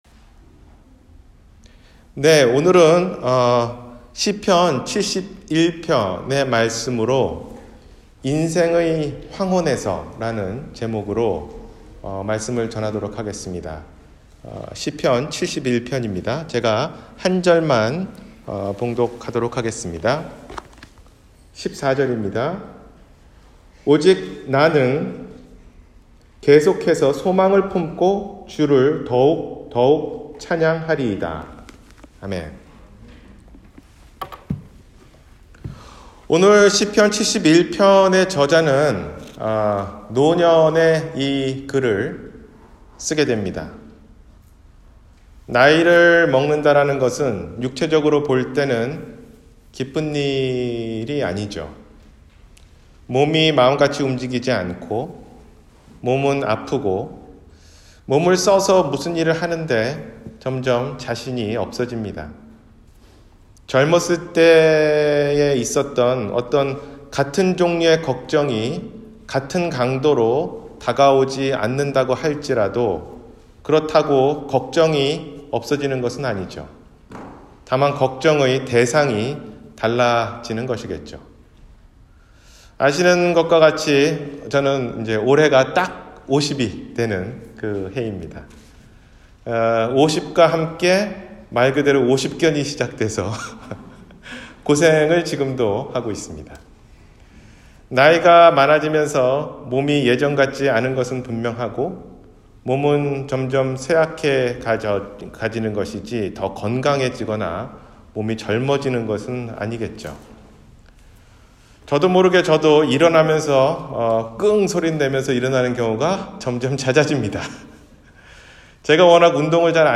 인생의 황혼에서 – 주일설교